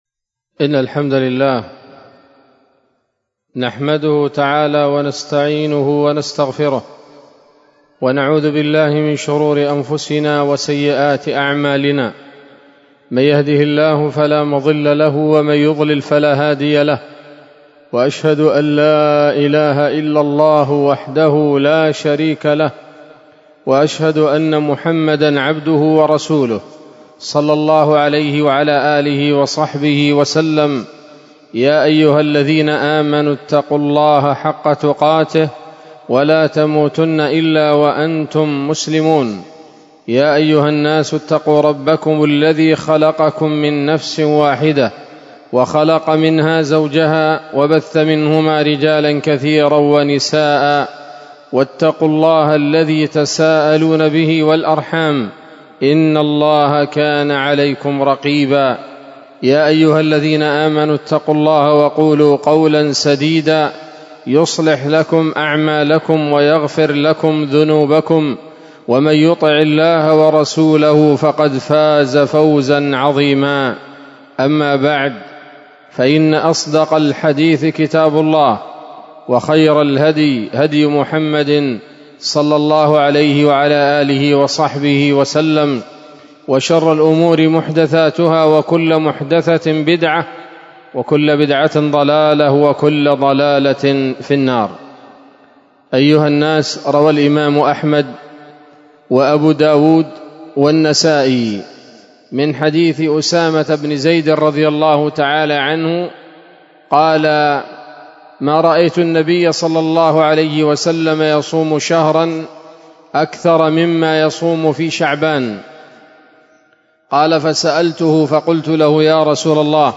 خطبة جمعة بعنوان: (( وظائف شهر شعبان )) 1 شعبان 1446 هـ، دار الحديث السلفية بصلاح الدين